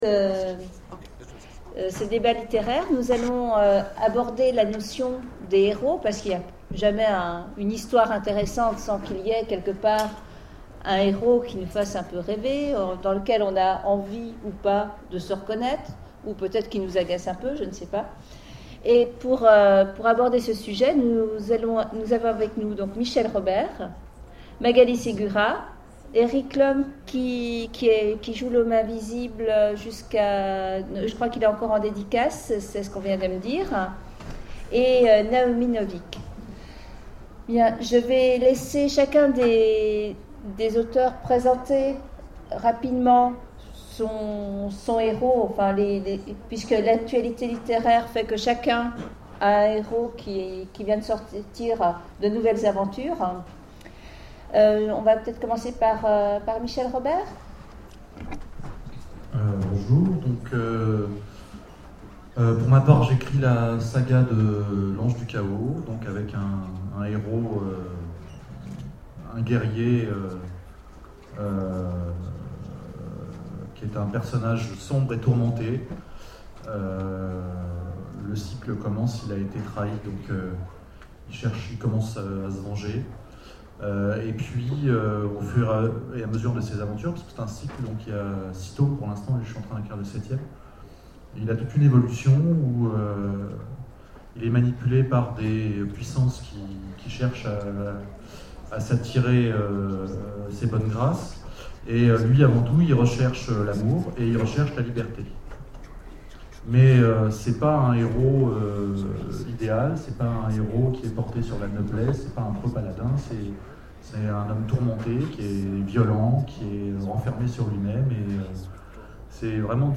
Mots-clés Quête Conférence Partager cet article